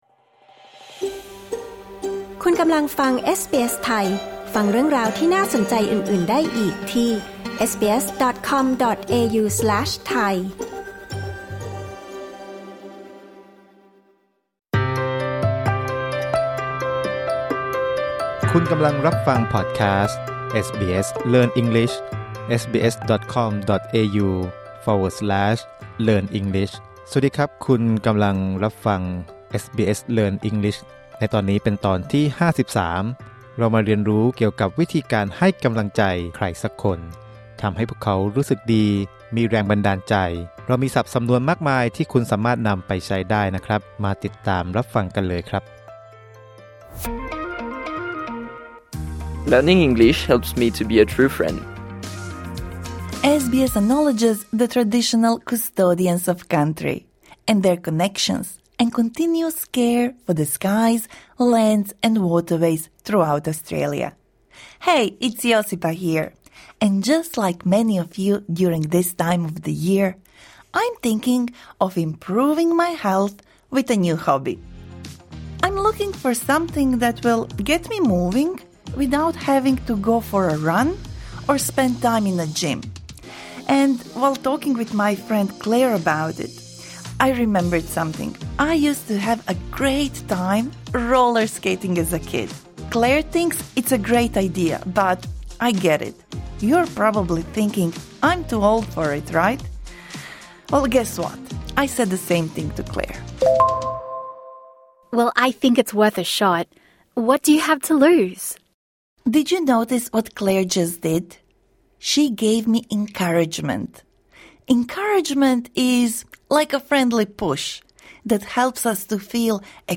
This lesson suits intermediate learners.